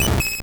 Cri de Mélofée dans Pokémon Or et Argent.